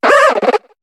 Cri de Flotoutan dans Pokémon HOME.